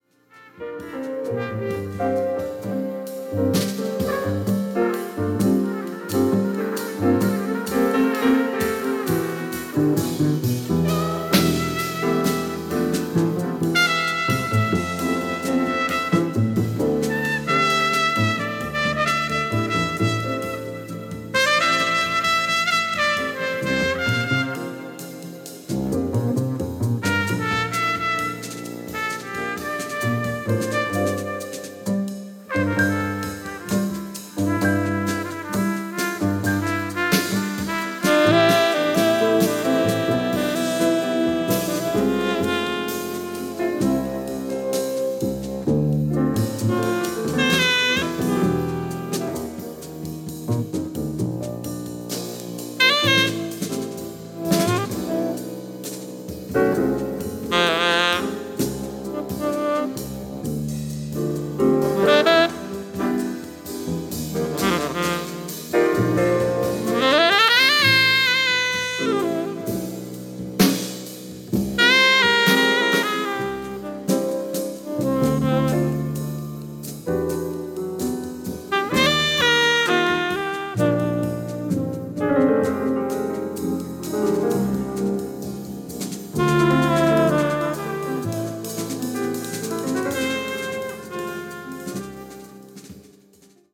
Bass
Drums
Saxophone
Trumpet